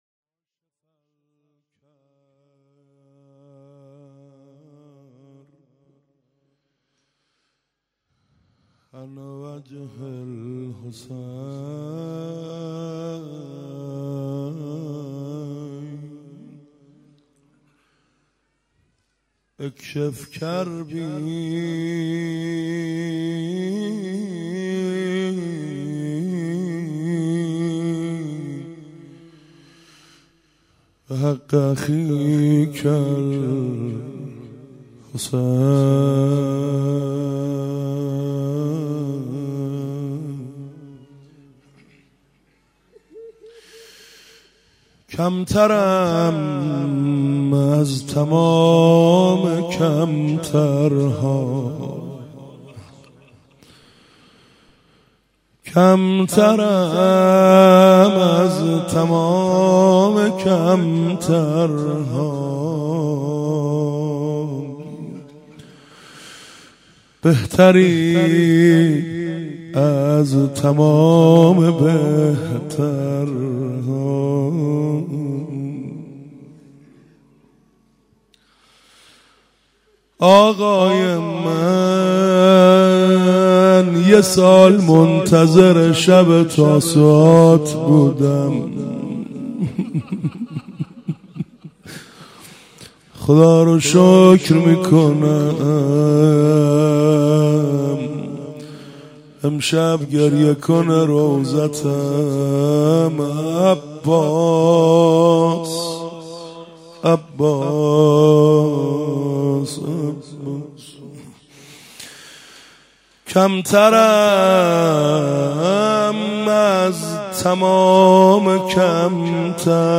شب نهم محرم (شب تاسوعا)
روضه